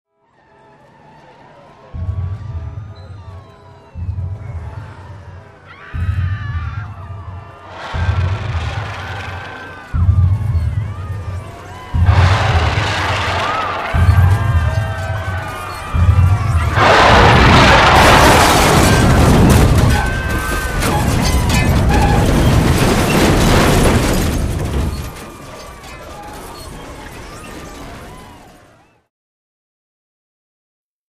City Ambience, Distant, Huge Foot Stomps, Sirens And Mayhem.